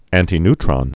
(ăntē-ntrŏn, -ny-, ăntī-)